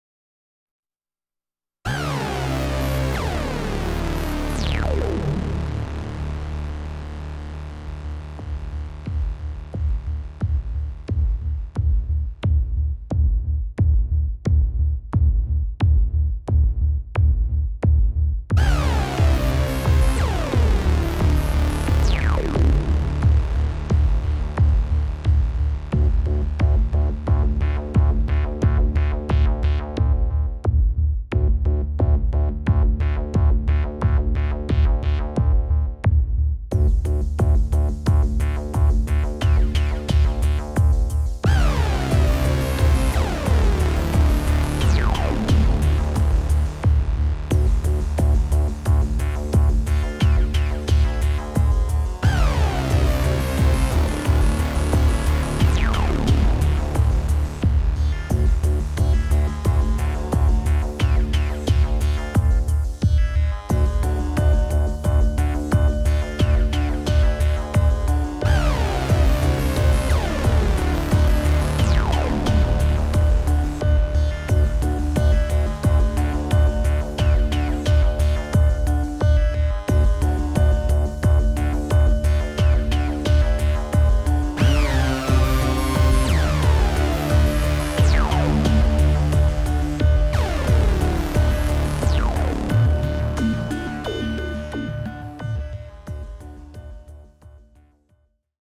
This is the Maximus 8V. It's much more than an 8 voice polysynth. It's an analogue workstation.